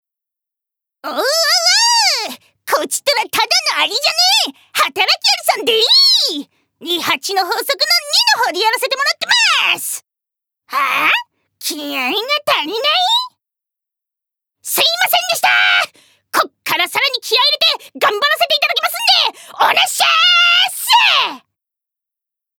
ボイスサンプル
セリフ２